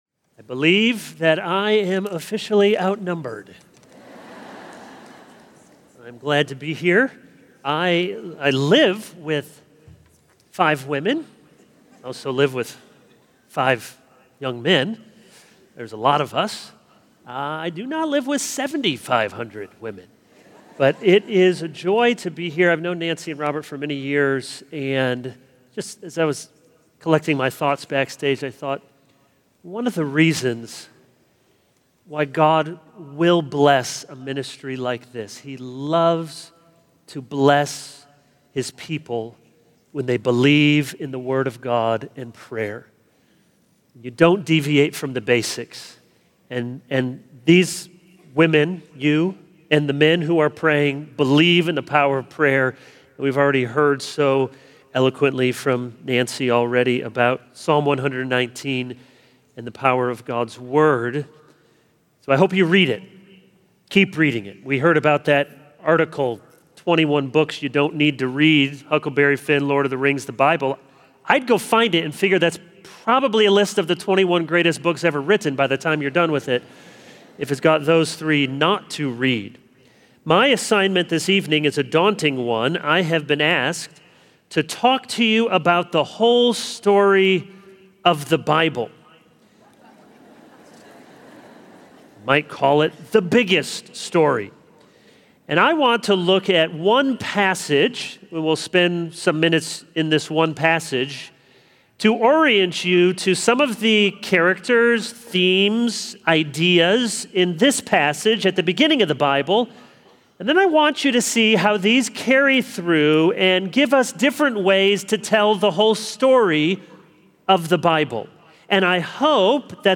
Behold the Big Story of the Bible | True Woman '25 | Events | Revive Our Hearts